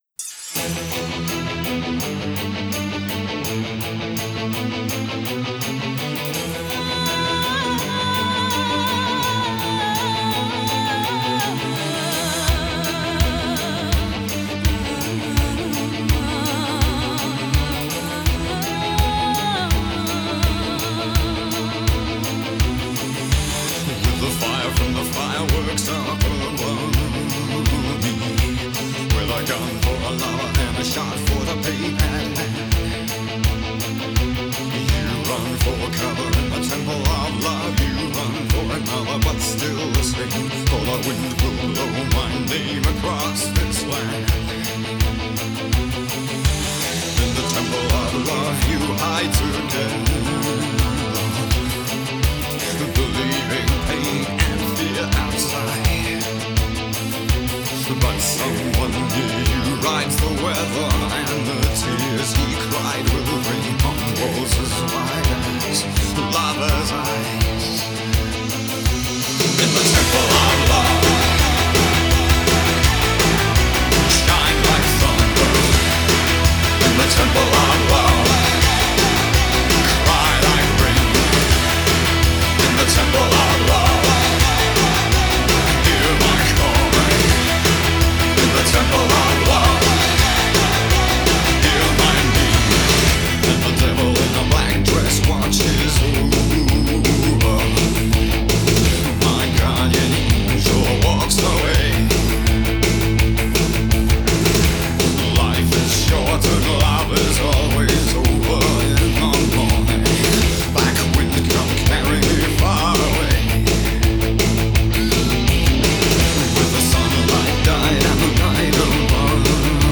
typically tubesound with triode and non-linear transformer:
This device produces the "warm", "interesting" sound of tube amplifiers by a pentode as triode. The tube-sound results from the non-linear characteristik of the tube Ik=kx(Ust)^3/2 and of the non-linear transformer.
Note: A simulation with a mono-wav-file in LTspice has shown that the result is good.
tube_temple_zv_250_1.wav